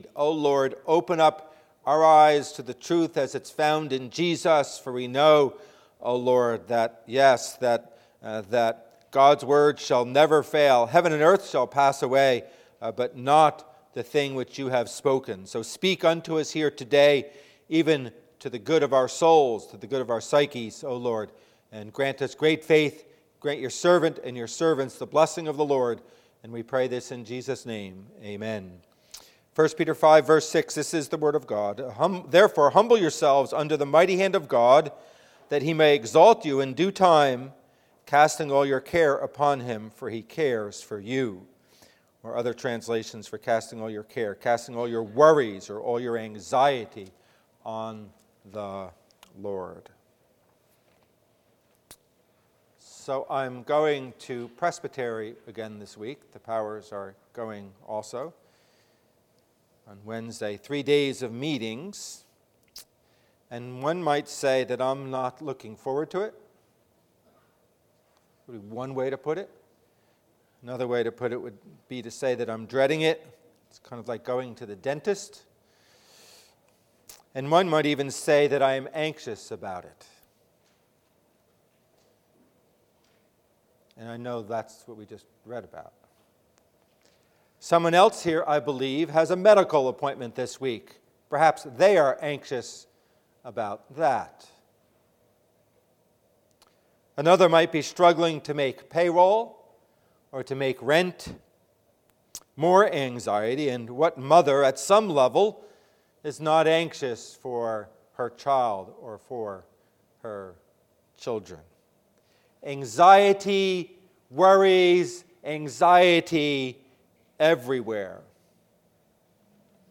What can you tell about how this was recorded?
Part III Passage: 1 peter 5:5-8 Service Type: Worship Service « You Are an Emotional Being.